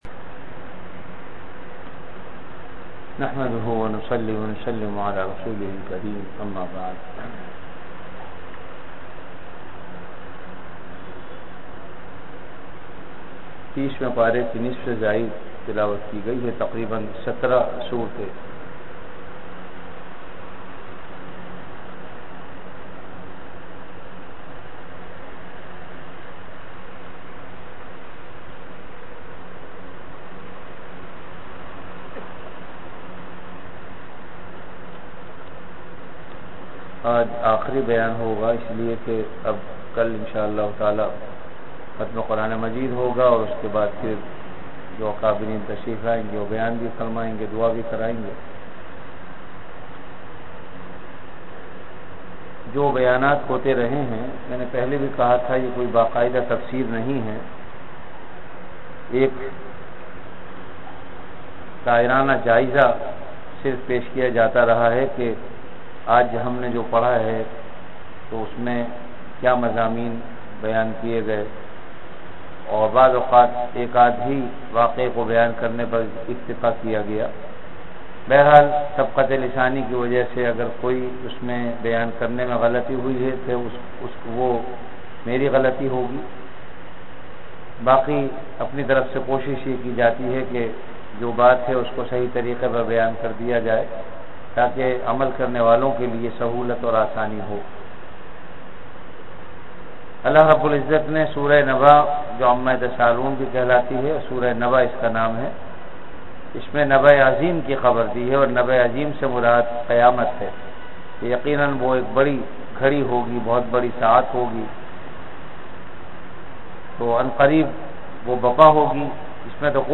Delivered at Jamia Masjid Bait-ul-Mukkaram, Karachi.
Ramadan - Taraweeh Bayan · Jamia Masjid Bait-ul-Mukkaram, Karachi